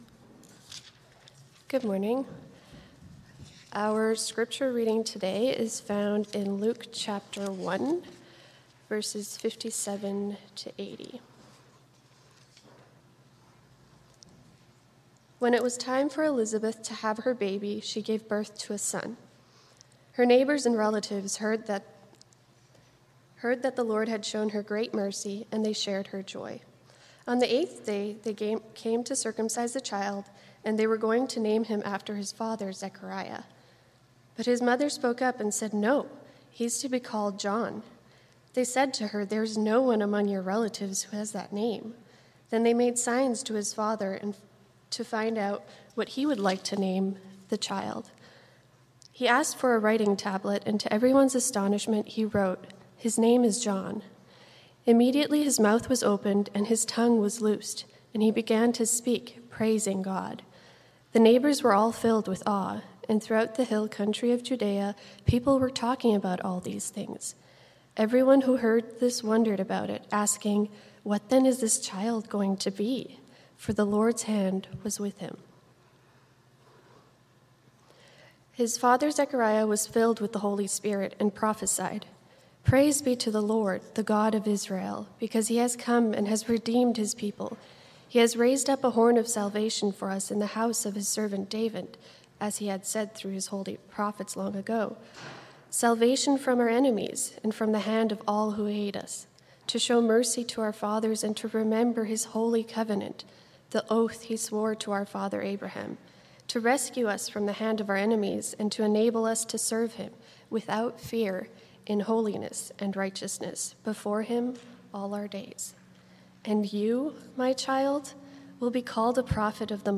with a prayer and reading of Holy Scripture
MP3 File Size: 38.6 MB Listen to Sermon: Download/Play Sermon MP3